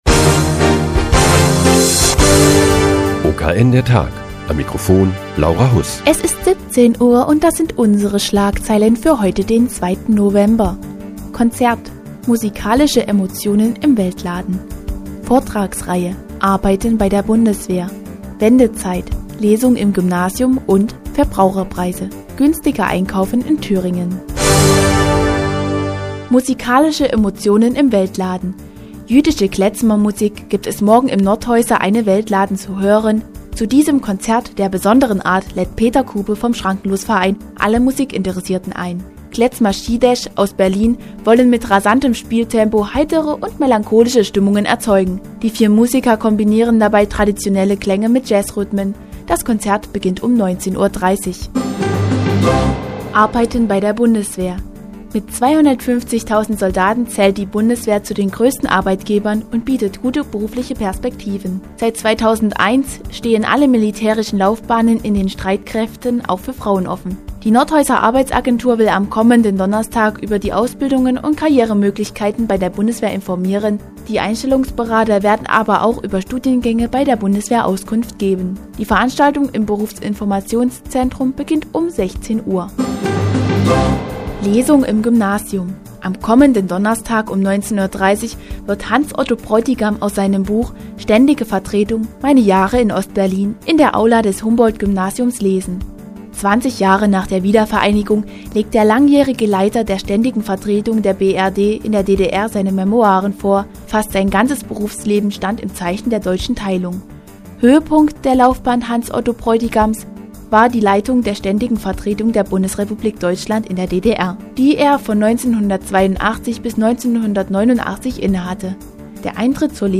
Die tägliche Nachrichtensendung des OKN ist nun auch in der nnz zu hören. Heute geht es um jüdische Klezmermusik im Eine-Welt-Laden und Karrieremöglichkeiten bei der Bundeswehr.